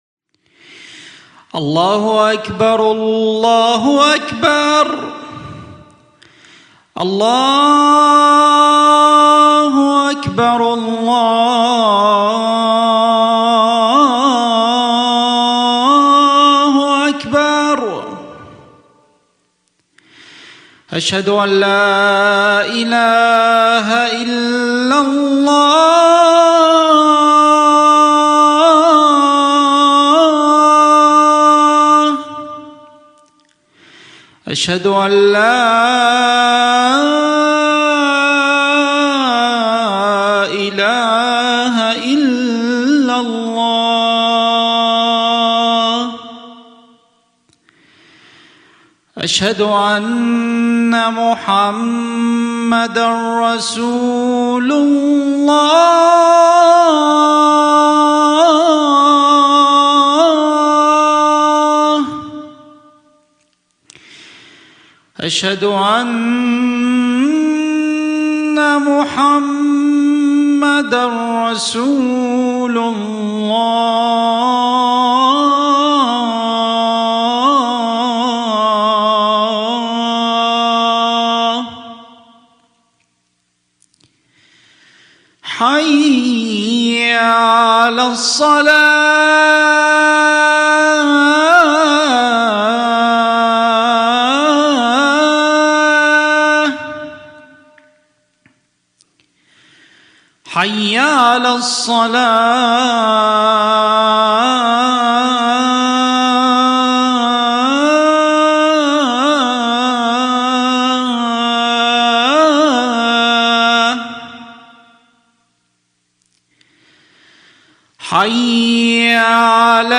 Muslim Community Center - MCC East Bay /Adhan (The Islamic Call to Prayer)